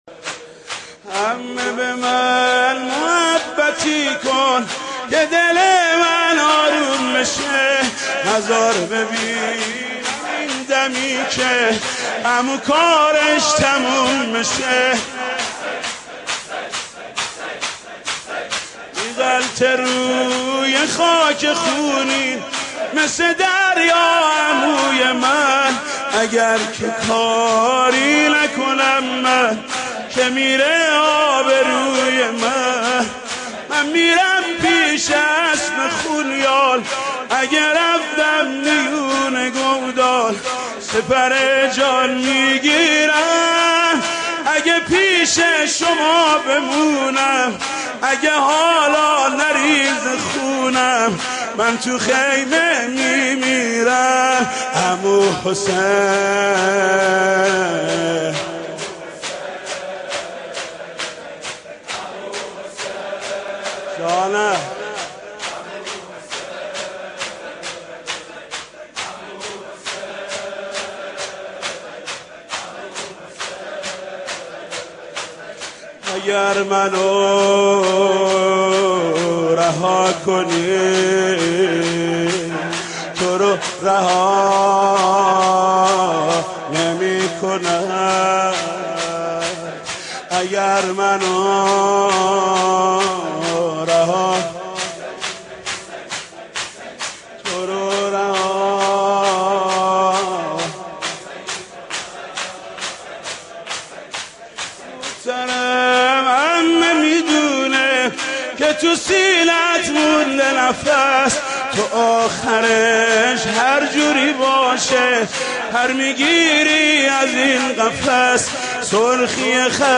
اشعار عبدالله ابن الحسن علیه السلام به همراه سبک/شور -( عمه به من محبتی کن )